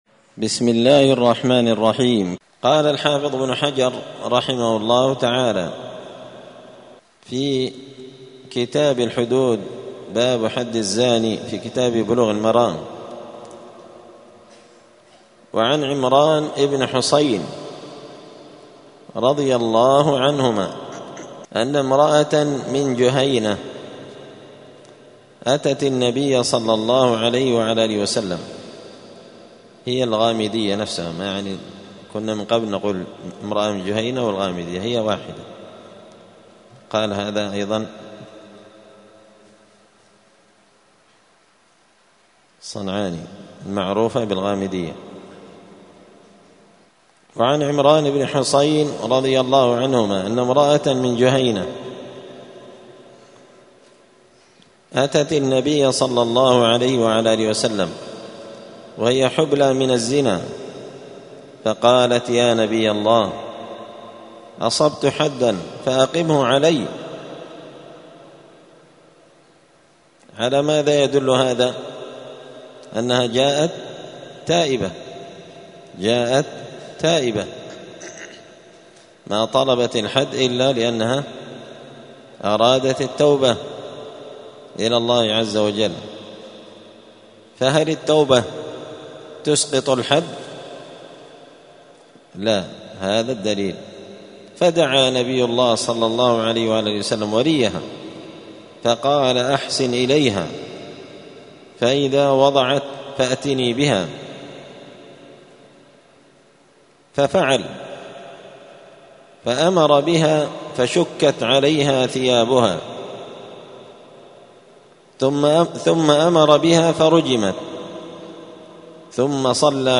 *الدرس الثامن (8) {باب حكم الصلاة على المرجوم بالزنا}*